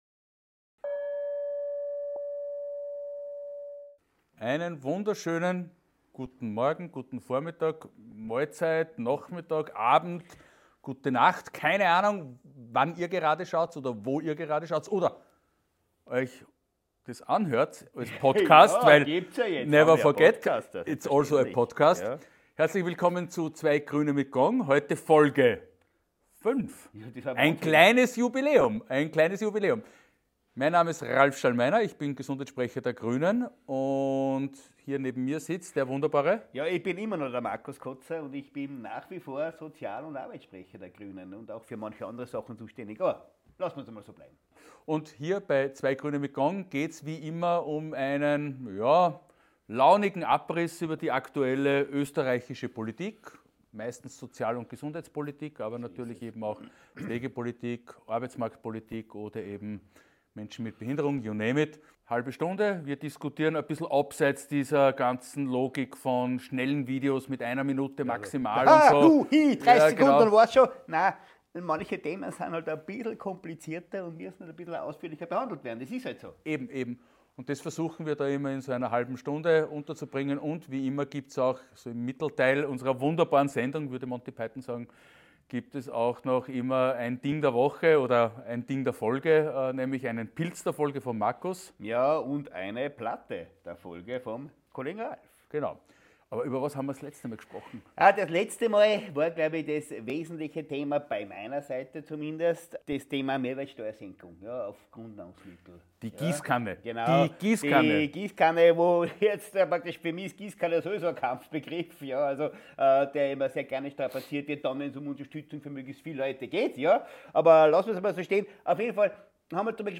Markus Koza und Ralph Schallmeiner haben ein neues Format gestartet, in dem sie euch regelmäßig ein aktuelle Themen vorstellen, erklären, worum es wirklich geht, und euch spannende Perspektiven mitgeben.